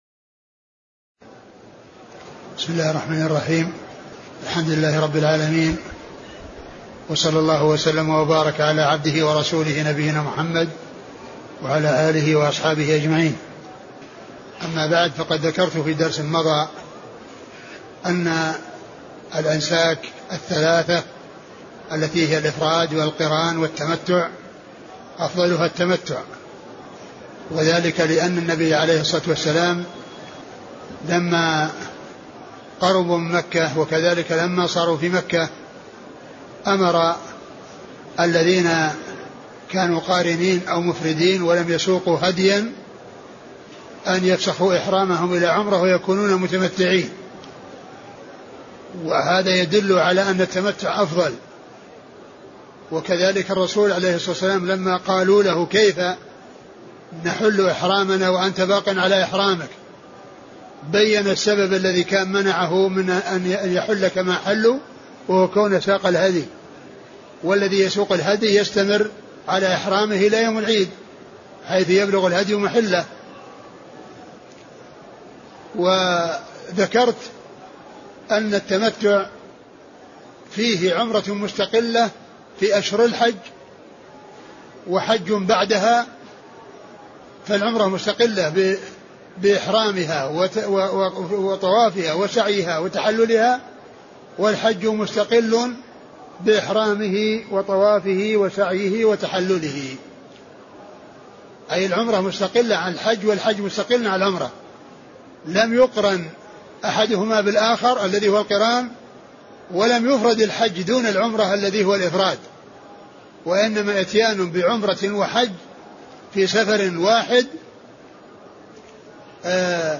محاضرة أنواع النسك
تاريخ النشر ٢٤ ذو القعدة ١٤٢٧ المكان: المسجد النبوي الشيخ: فضيلة الشيخ عبدالمحسن بن حمد العباد البدر فضيلة الشيخ عبدالمحسن بن حمد العباد البدر أنواع النسك